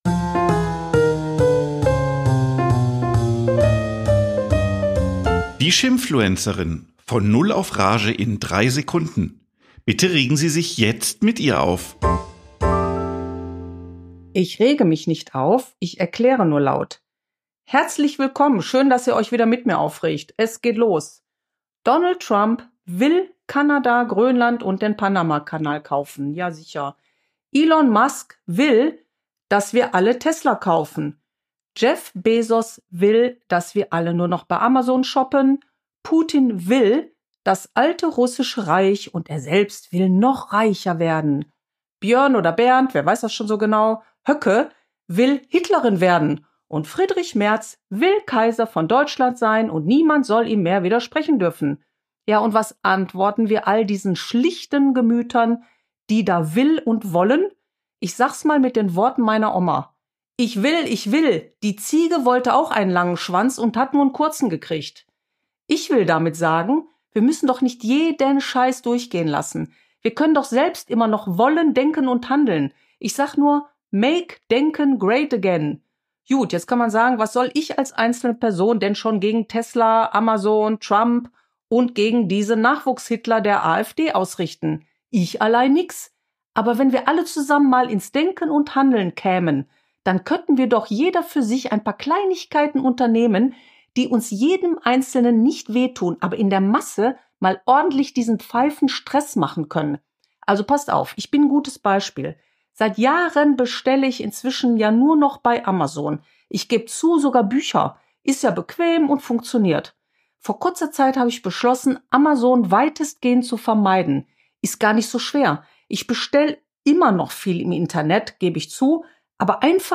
Eine Frau regt sich auf